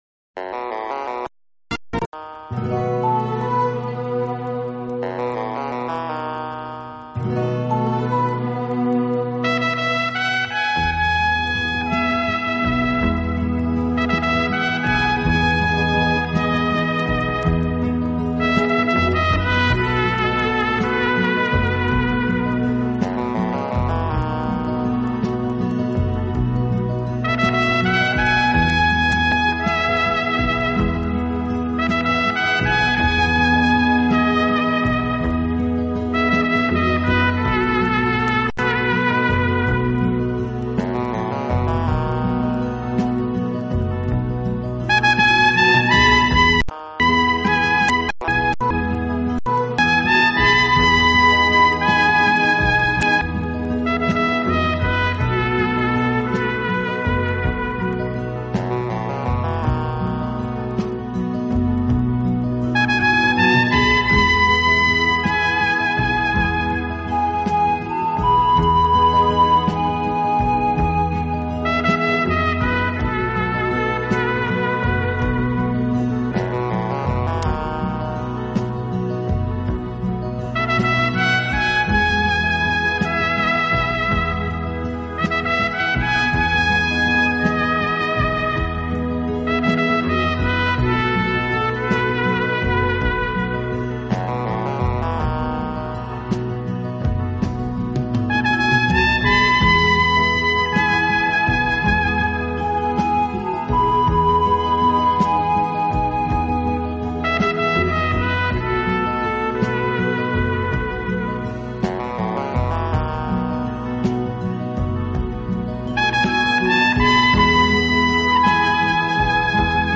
乐器名称：小号（Trumpet） 乐器本调：降B调。